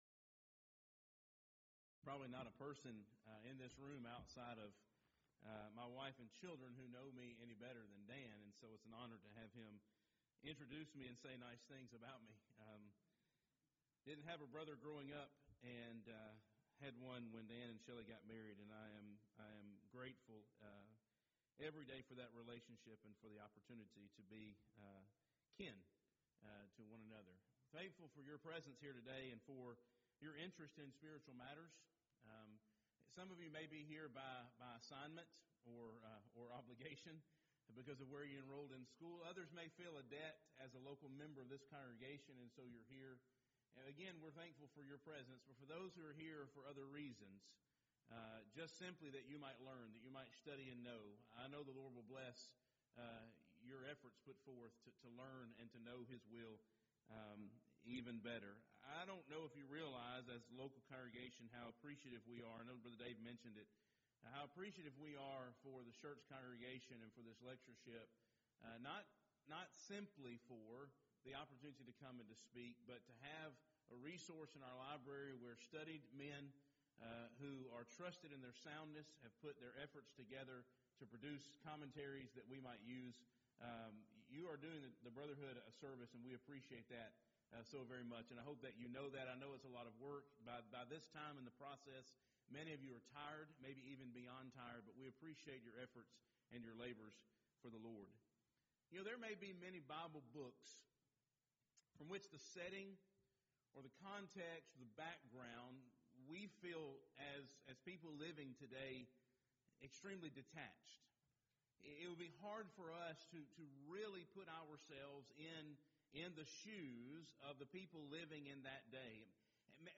Schertz Lectureship
15th Annual Schertz Lectures